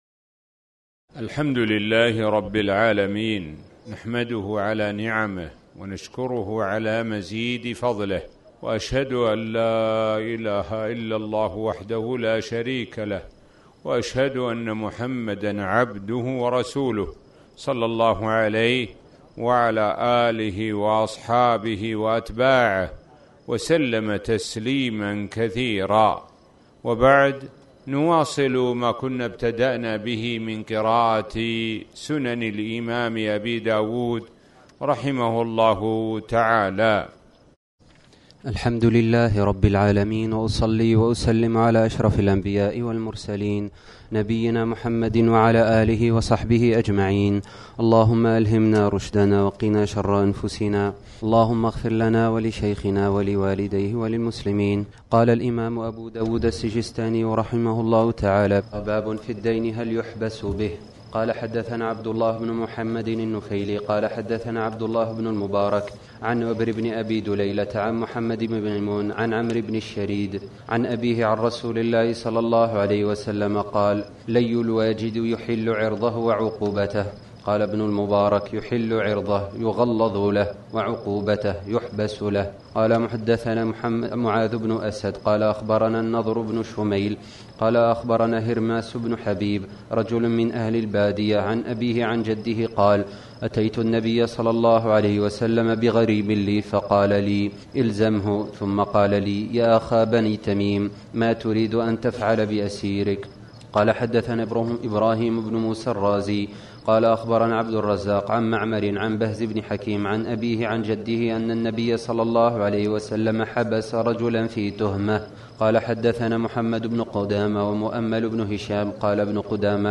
تاريخ النشر ٩ رمضان ١٤٤٠ هـ المكان: المسجد الحرام الشيخ: معالي الشيخ د. سعد بن ناصر الشثري معالي الشيخ د. سعد بن ناصر الشثري كتاب القضاء